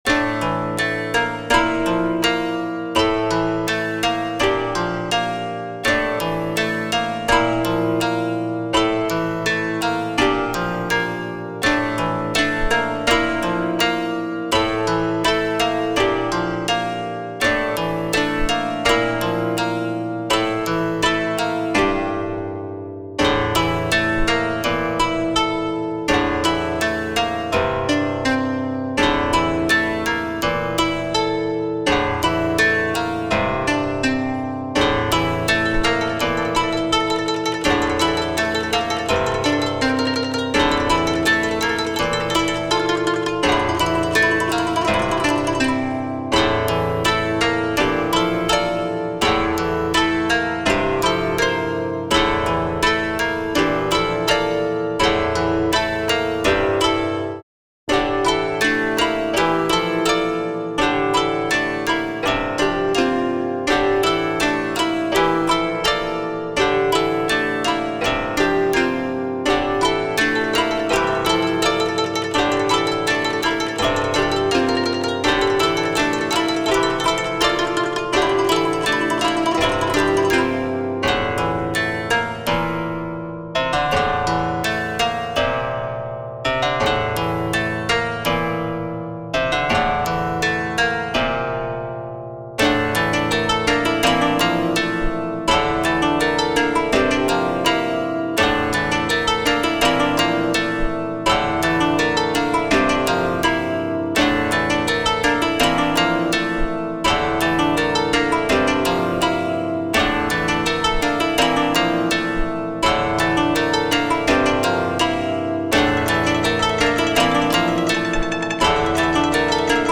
和風オルゴール楽曲の琴アレンジ。